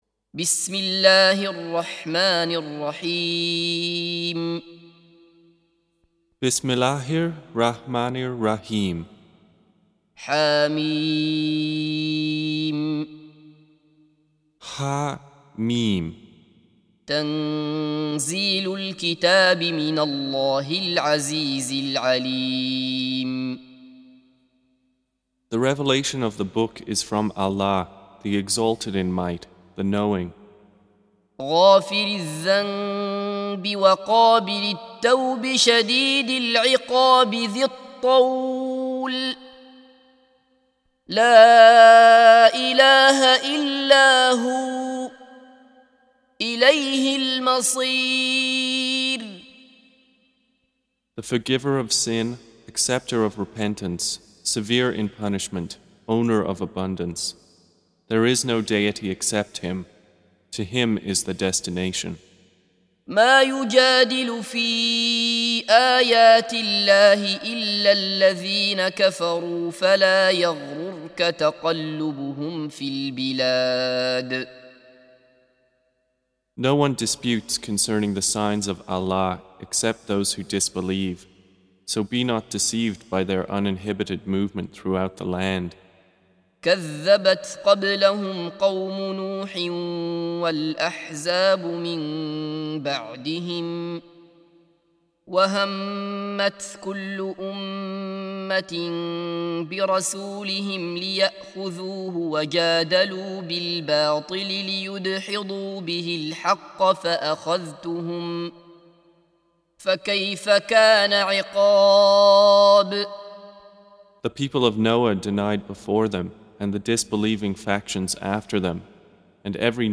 Audio Quran Tarjuman Translation Recitation
Surah Repeating تكرار السورة Download Surah حمّل السورة Reciting Mutarjamah Translation Audio for 40. Surah Gh�fir سورة غافر N.B *Surah Includes Al-Basmalah Reciters Sequents تتابع التلاوات Reciters Repeats تكرار التلاوات